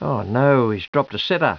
Commentary
Click here for the latest audio samples from Richie!